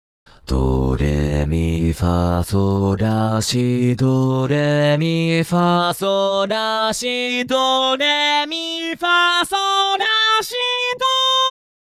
【説明】 ： 最もミニマルながら強力なパフォーマンス
いわゆるキレ音源と呼ばれるタイプの音源です